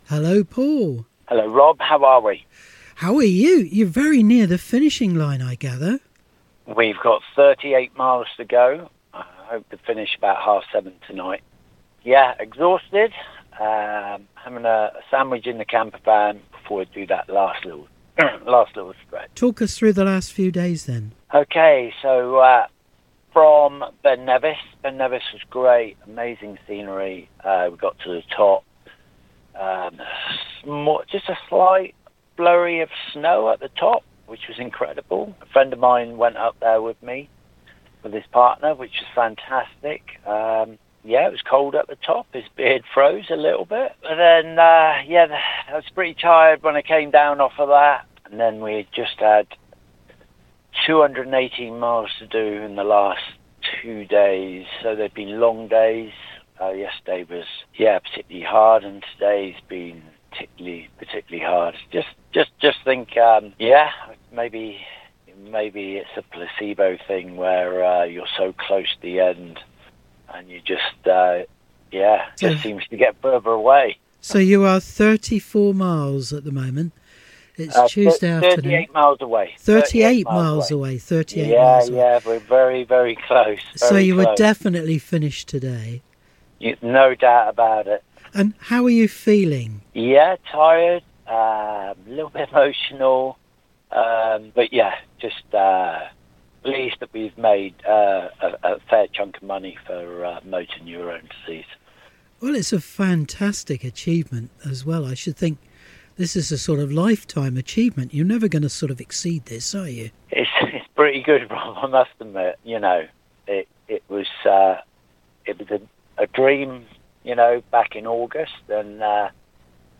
phoned in progress reports